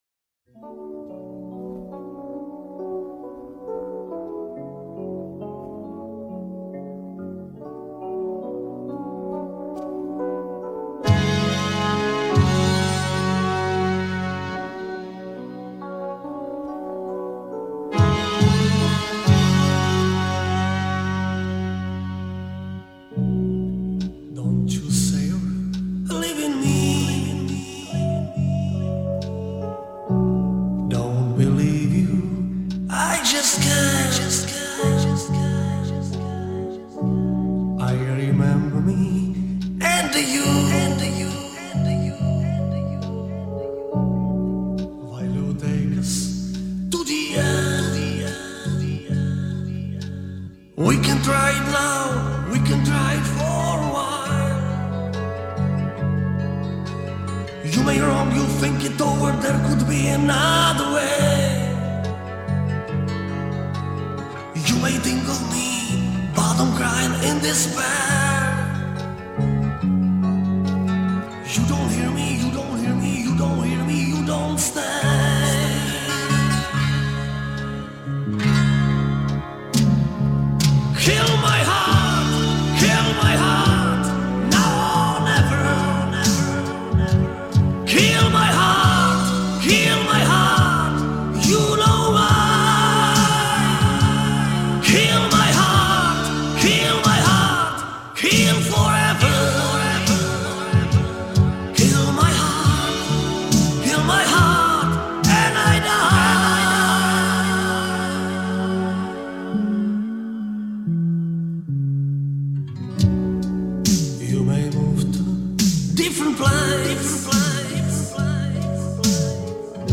Так было оцифровано моим приятелем с винила.